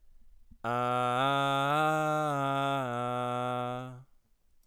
Eklige Resonanz bei Vocals - Was tun ?!
Die Resonanzen sind immer noch da... Das klingt echt als hätte man eine Wespe im Hals Jetzt bin ich total ratlos!
Es wird übrigens tatsächlich das ID22) Hier ein weiterer Test einer Vocal Aufnahme (mit dem Behringer C-1 ): Anhänge Test Wespe 2.wav Test Wespe 2.wav 1,6 MB · Aufrufe: 259